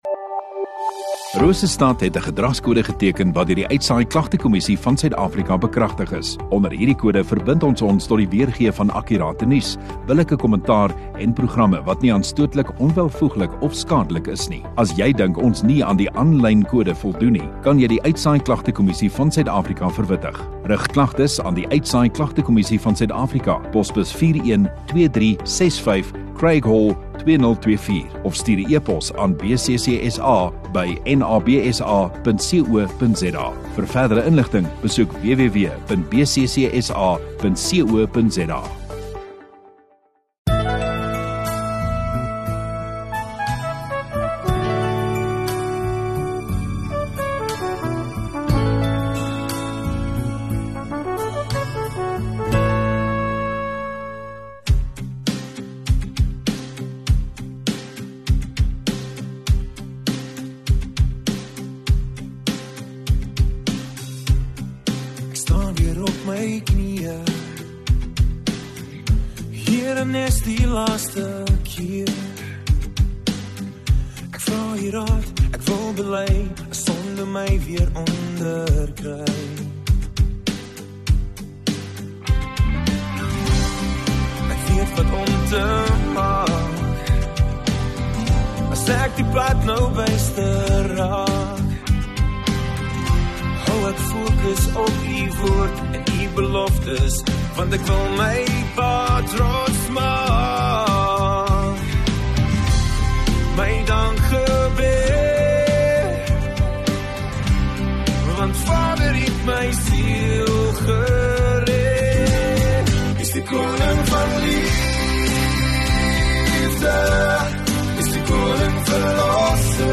22 Nov Saterdag Oggenddiens